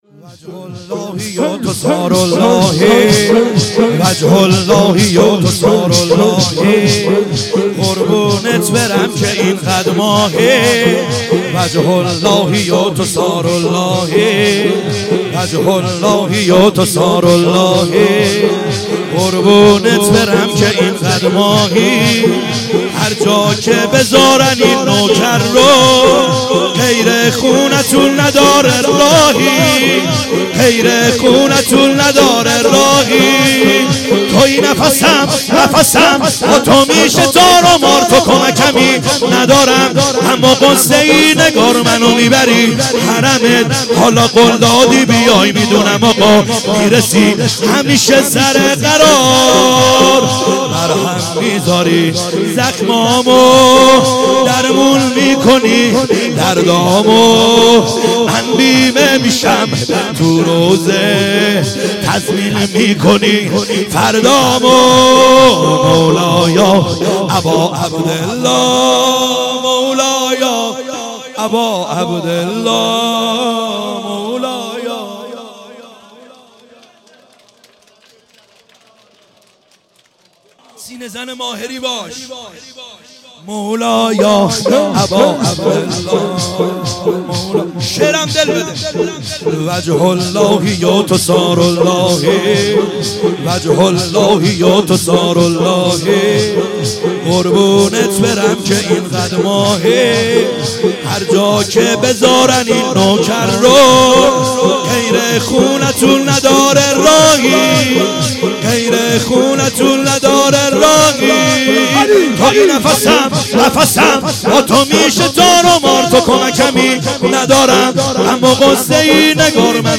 خیمه گاه - بیرق معظم محبین حضرت صاحب الزمان(عج) - شور | وجه اللهی تو ثاراللهی